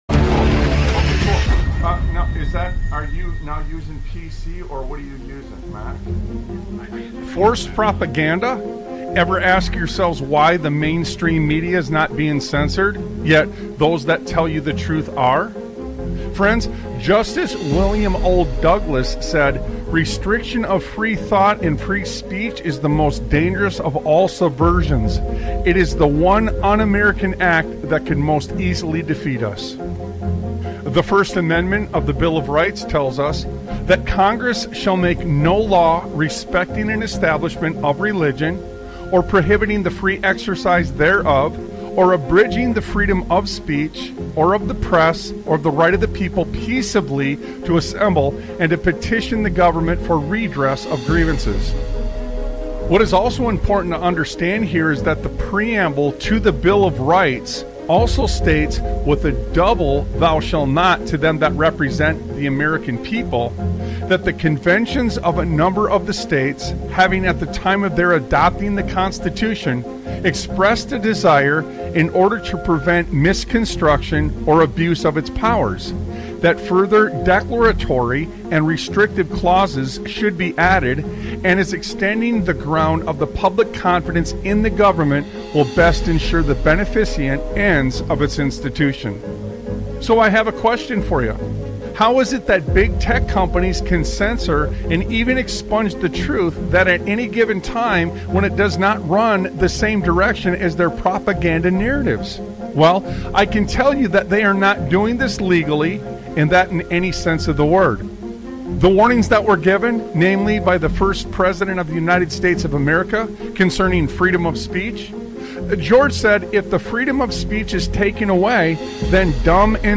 Talk Show Episode, Audio Podcast, Sons of Liberty Radio and What You Believe May Not Be True on , show guests , about What You Believe May Not Be True, categorized as Education,History,Military,News,Politics & Government,Religion,Christianity,Society and Culture,Theory & Conspiracy